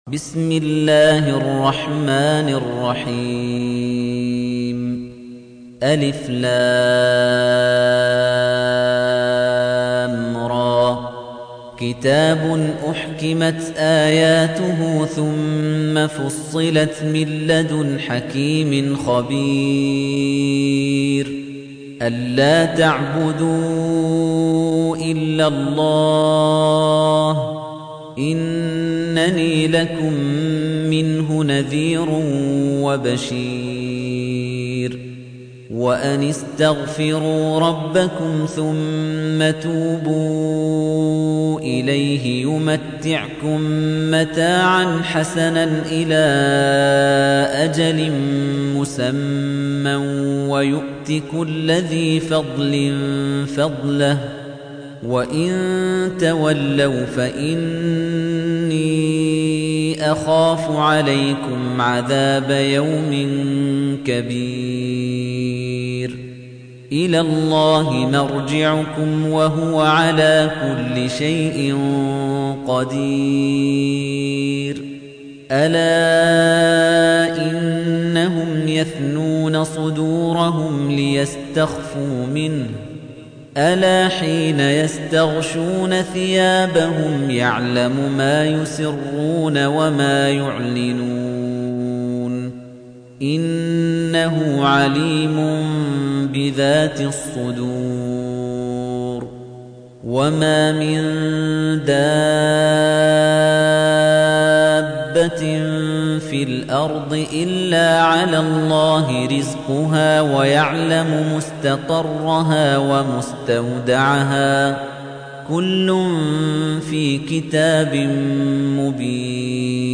تحميل : 11. سورة هود / القارئ خليفة الطنيجي / القرآن الكريم / موقع يا حسين